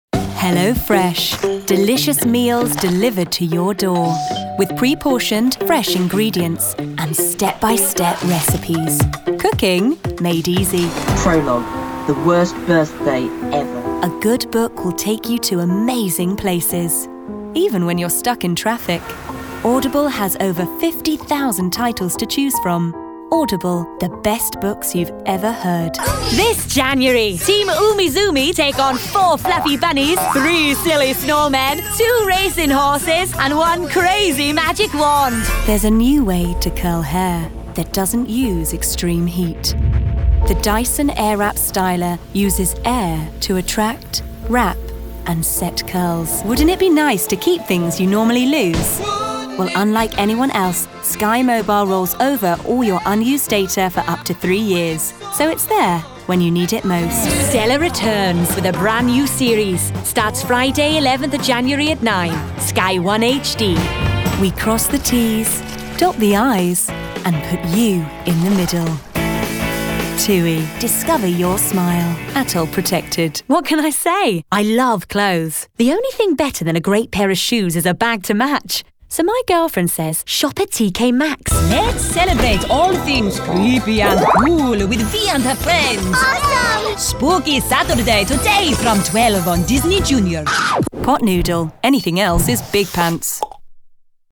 Commercial Showreel
Female
British RP
Neutral British
Bright
Friendly
Confident
Warm